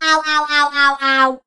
robo_death_02.ogg